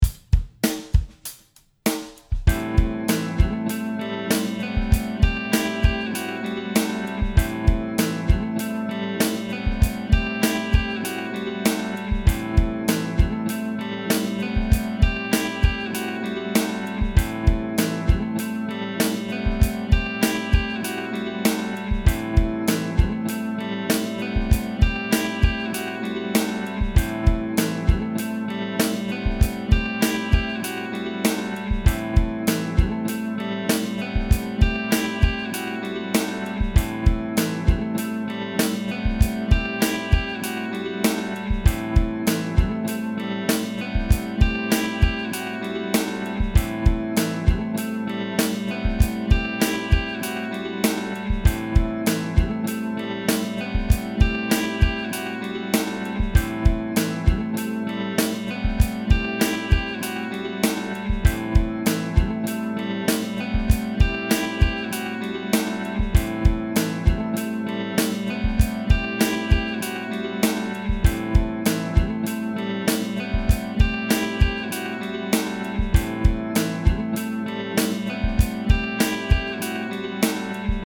Für die Pentatonik Bass Übungen verwenden wir als Harmonie einen A-7 = A Moll 7 Akkord.
Hier kannst du dir die Backing Tracks ohne Bassspur downloaden:
Download Drums und Keyboard Drone